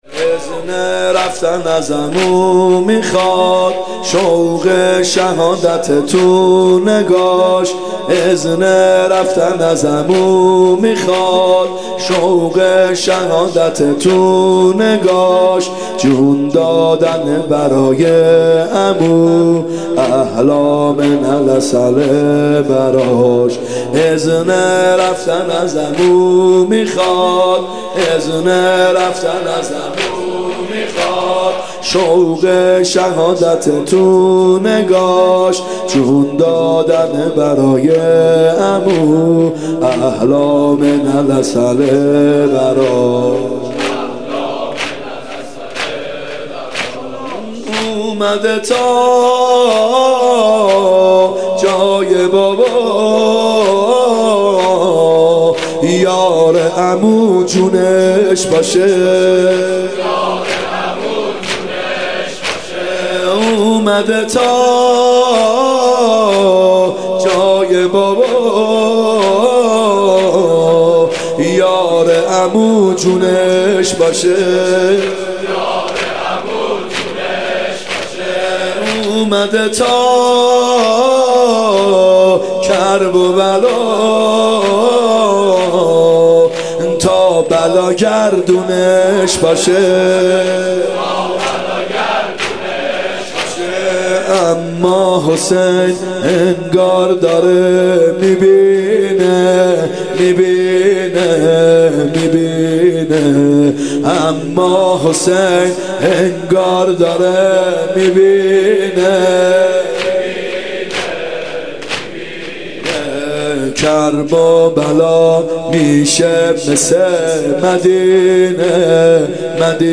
محرم 94(هیات یا مهدی عج)